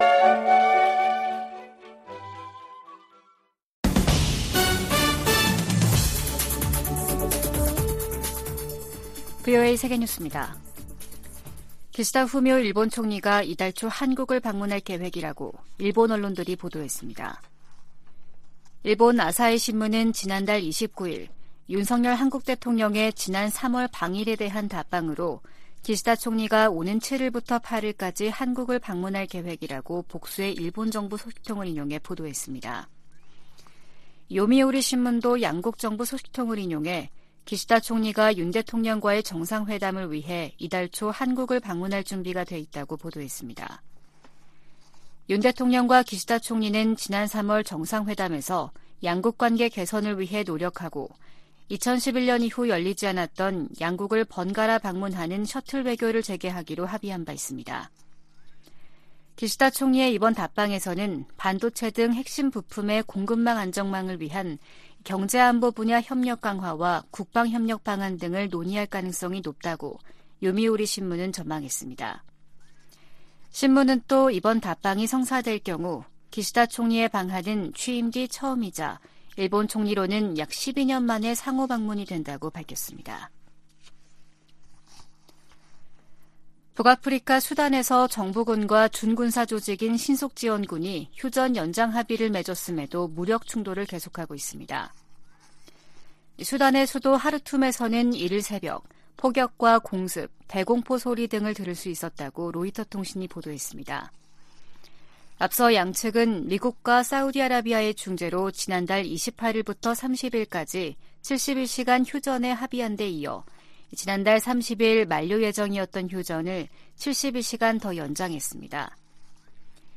VOA 한국어 아침 뉴스 프로그램 '워싱턴 뉴스 광장' 2023년 5월 2일 방송입니다. 김여정 북한 노동당 부부장이 ‘결정적 행동’을 언급해 대형 도발에 나설 가능성을 시사했습니다. 윤석열 한국 대통령이 하버드대 연설에서 워싱턴 선언에 포함된 한국의 의무를 거론하며 독자 핵개발에 선을 그었습니다. 12년 만에 이뤄진 한국 대통령의 미국 국빈 방문은 한층 강화된 양국 관계를 보여줬다고 캐서린 스티븐스 전 주한 미국대사가 평가했습니다.